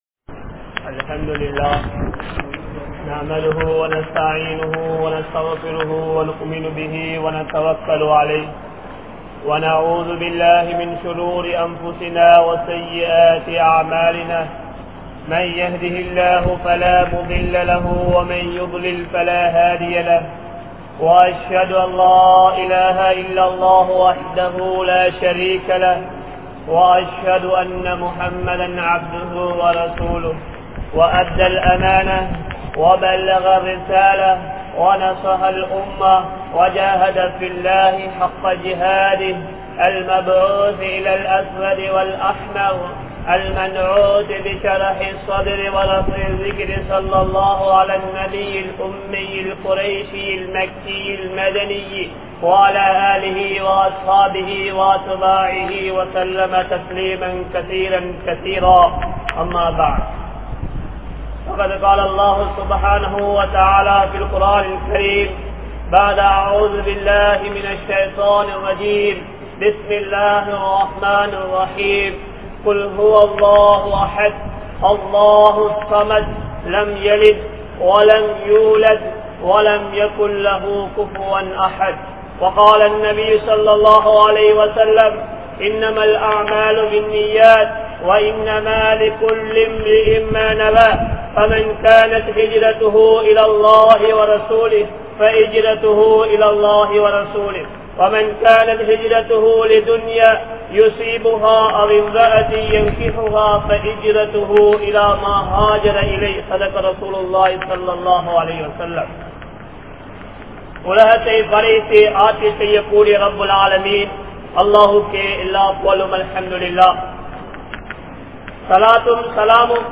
Allah Irakkap Padaatha Manitharhal (அல்லாஹ் இரக்கப்படாத மனிதர்கள்) | Audio Bayans | All Ceylon Muslim Youth Community | Addalaichenai
Muhideen Jumua Masjith